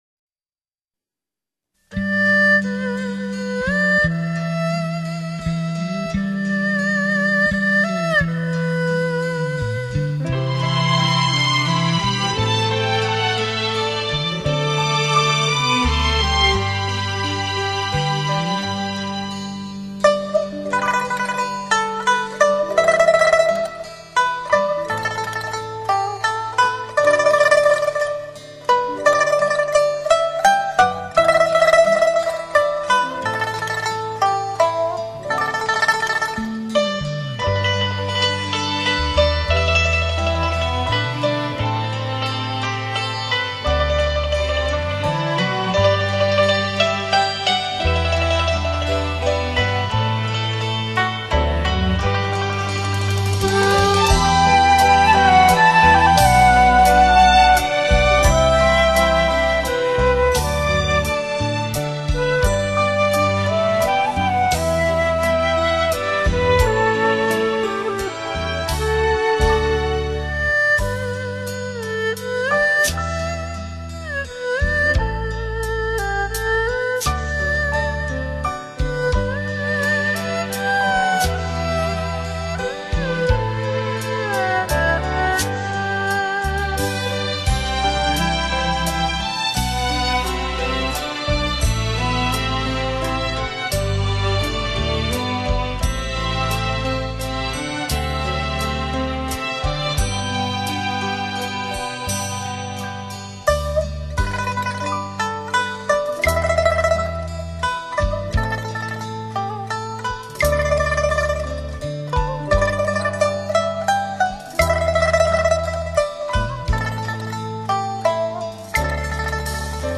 专辑歌手：纯音乐
民族乐器与电子乐的完美结合，演绎着一首首经典动听老歌，顾名思义“怀旧篇”。